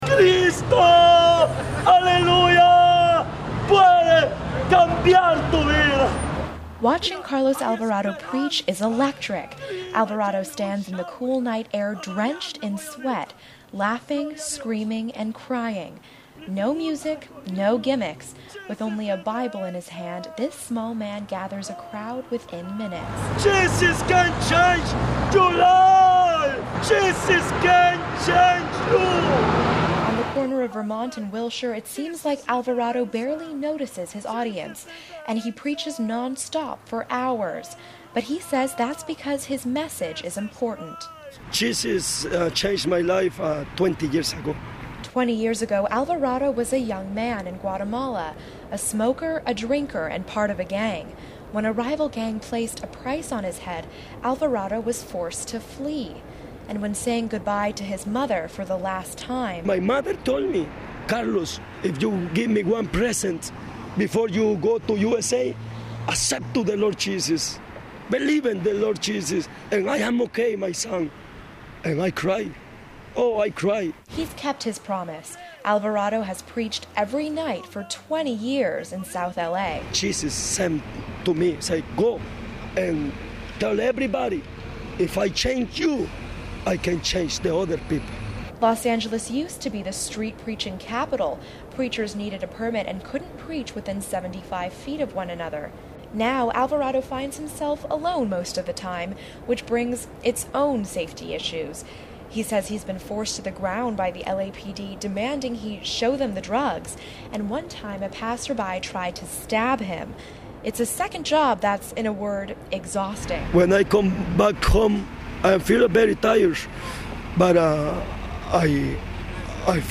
“I have never seen a street preacher in my entire life,” said one passerby.